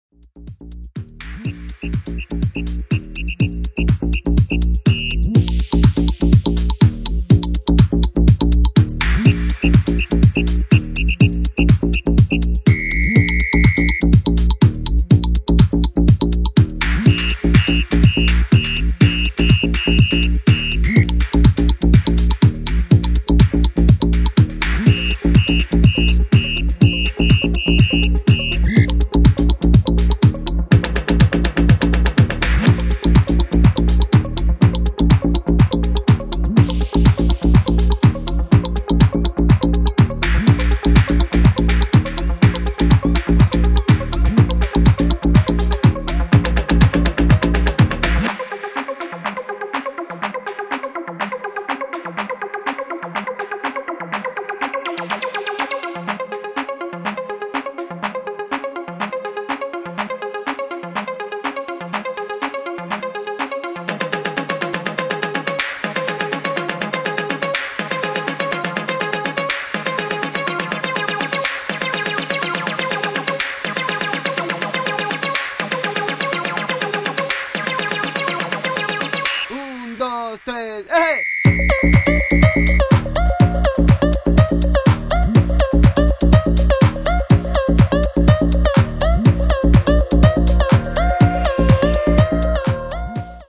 ジャンル(スタイル) NU DISCO / ELECTRONICA / BALEARICA